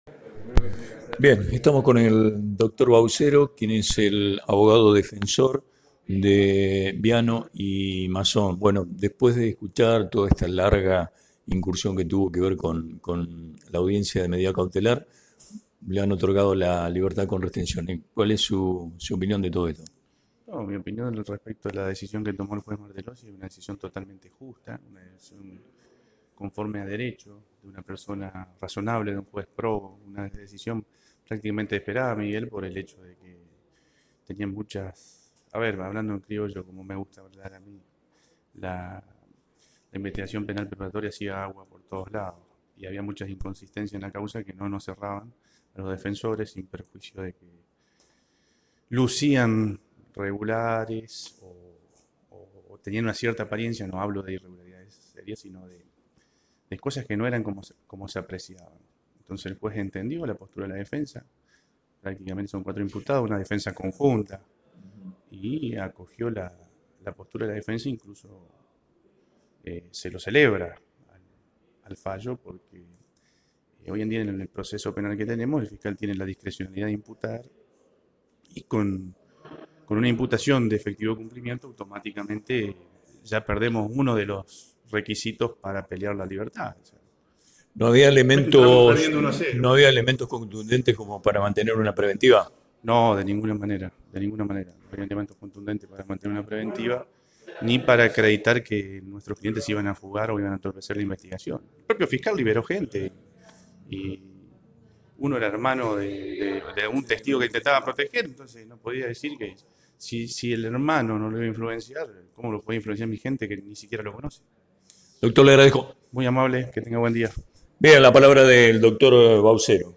Entrevista al fiscal Alejandro Rodríguez